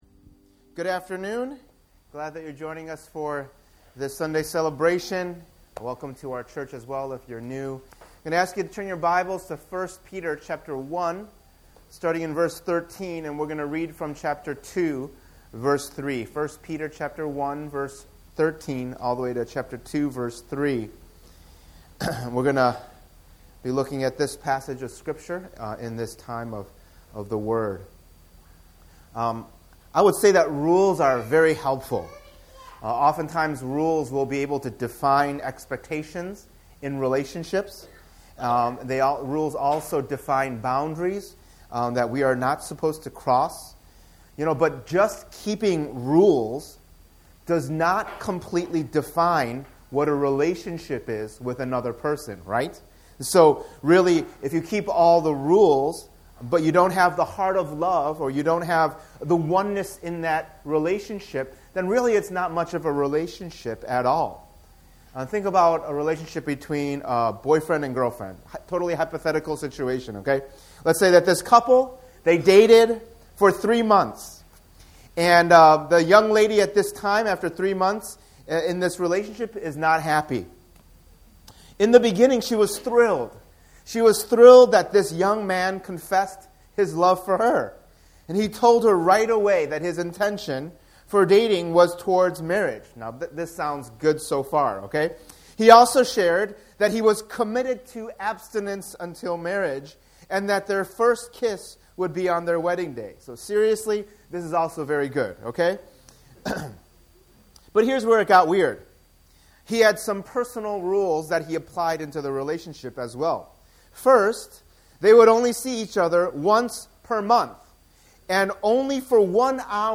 In this sermon series, we’ll be looking through Apostle Peter’s First Letter to different churches in the first century amidst a time of great suffering and persecution, and we’ll see different aspects of what God calls us to as disciples of Jesus Christ: 1: Experiencing Salvation 2: Growing Up Into Salvation 3: Knowing Who We Are Together 4: Living as Servants 5: Living as Wives & Husbands 6: Suffering for Doing Good 7: Glorifying God in Everything 8: Suffering as a Christian 9: Standing Firm as the Church